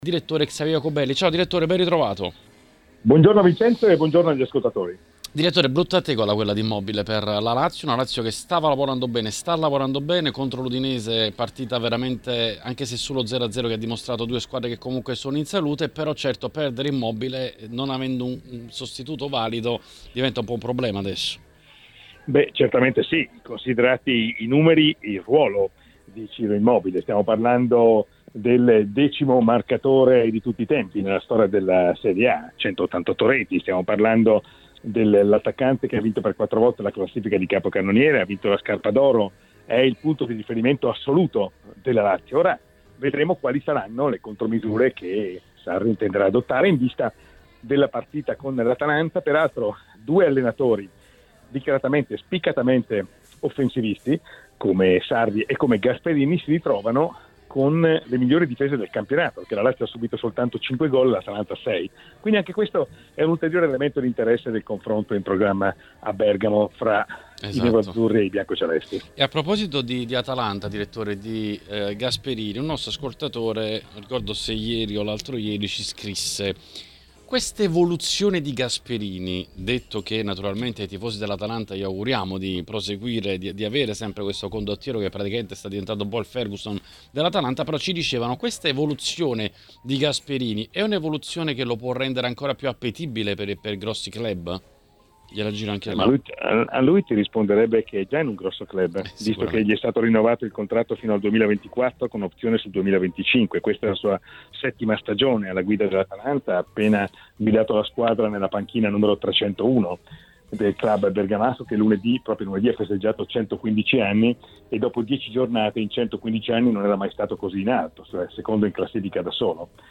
Nel corso dell'appuntamento odierno con L'Editoriale è intervenuto ai microfoni di TMW Radio Xavier Jacobelli.